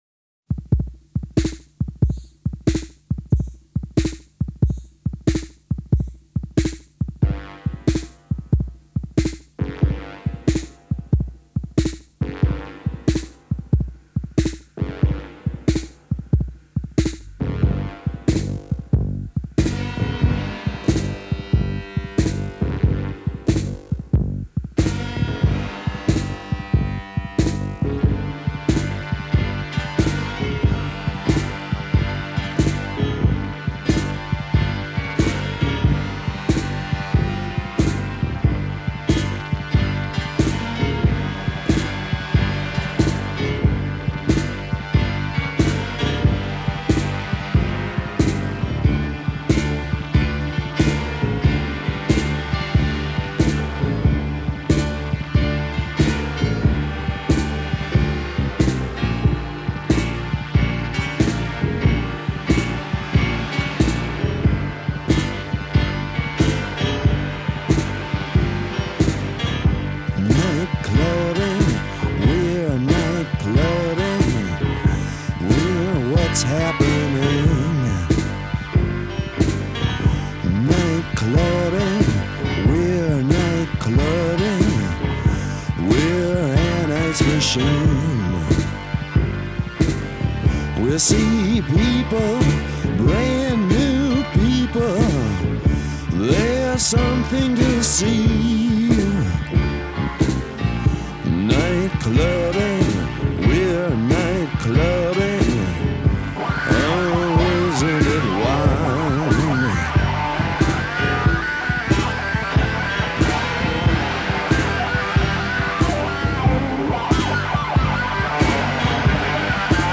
louche, sleazy and vaguely sinister
pounds out the skewed honky tonk blues on the upright piano